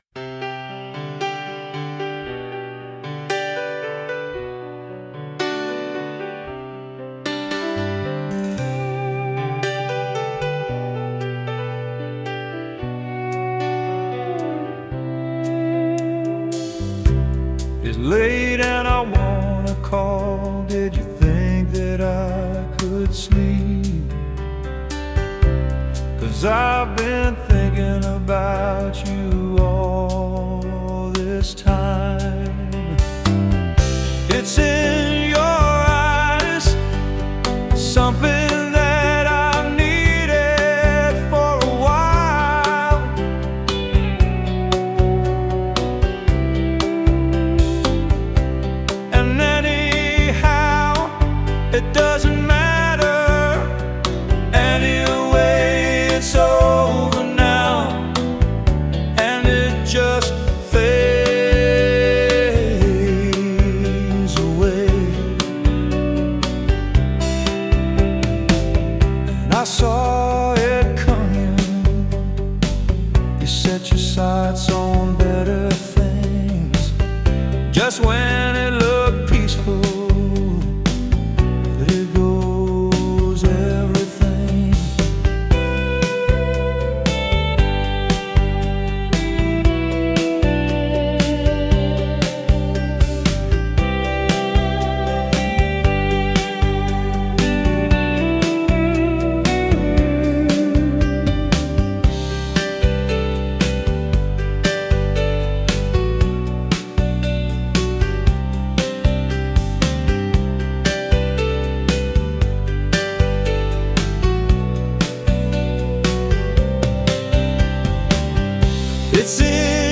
Piano Pop / Indie Pop / Adult Contemporary
(leans into emotional singer-songwriter territory)
Approximately 72–78 BPM
• Piano as the emotional anchor
• Subtle atmospheric elements
• Minimal rhythm section (designed to support, not distract)